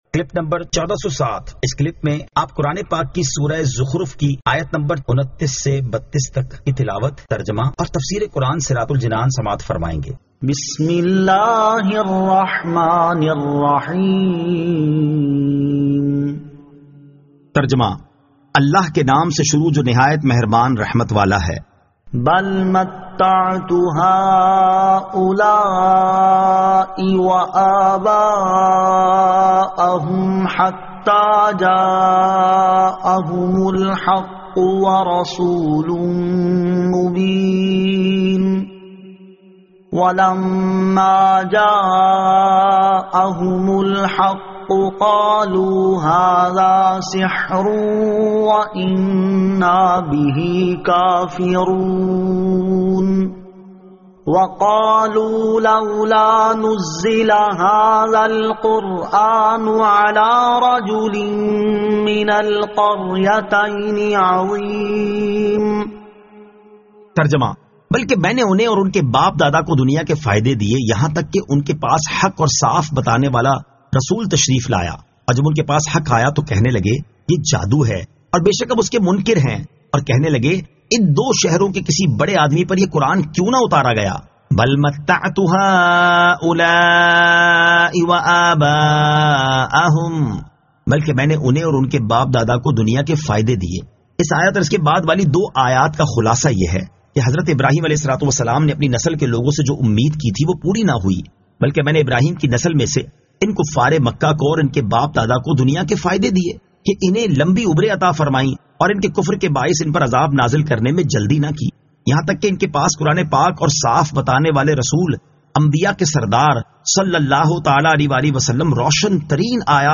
Surah Az-Zukhruf 29 To 32 Tilawat , Tarjama , Tafseer